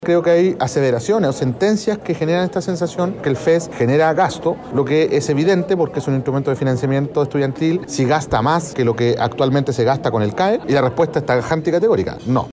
Por su parte, el ministro de Educación, Nicolás Cataldo, aseguró que estas observaciones no son nuevas y que las vienen trabajando con el CFA hace meses.